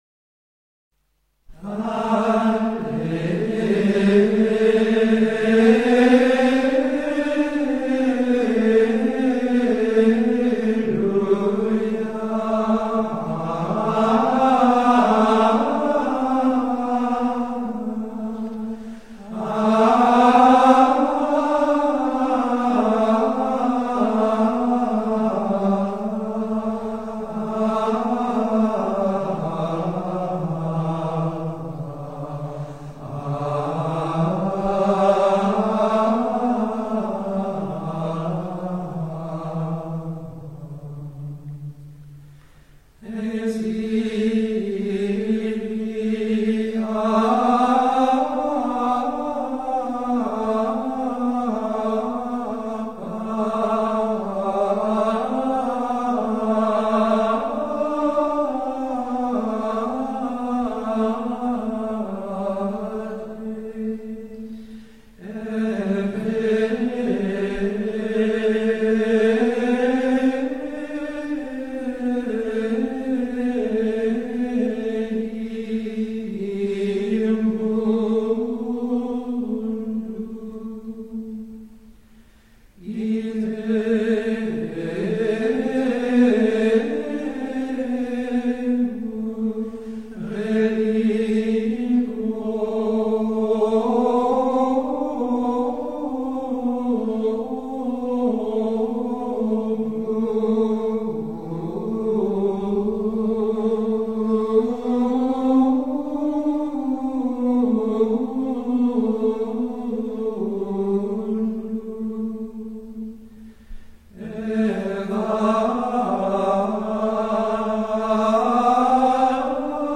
Saint René Goupil • Gregorian Chant Propers
2nd Alleluia • Score